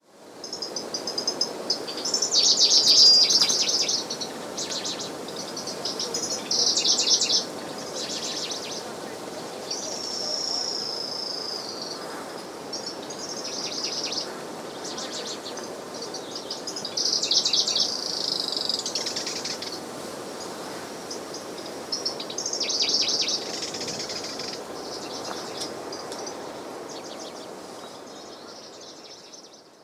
Song with element of call at beginning. Recorded 27 June in lowland grassland/tussocky tundra near northernmost extension of breeding range.